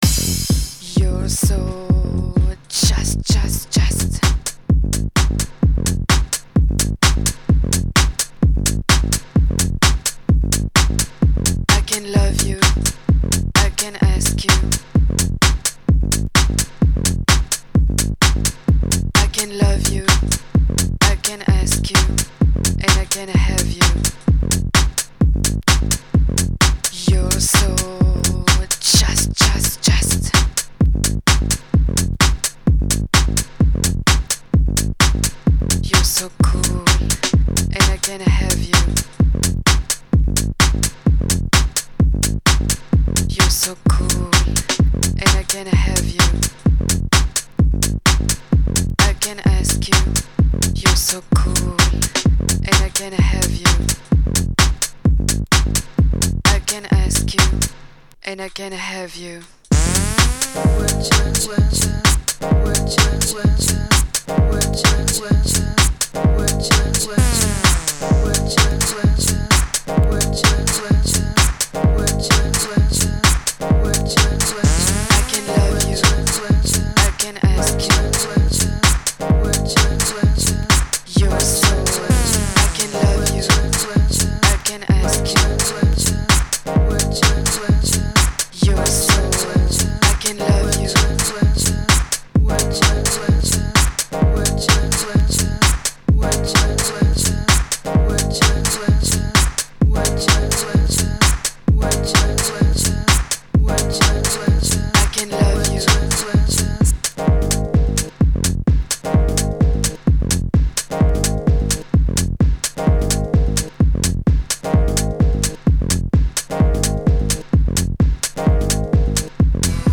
Muzyka/Music: House/Disco/Minimal Techno/Funk
Exclusive DJ mix session.